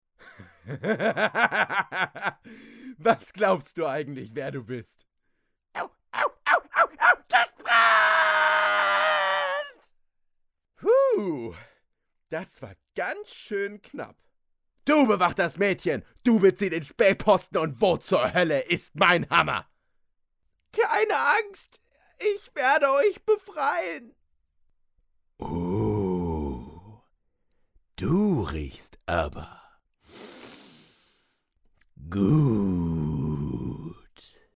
Games/Chargen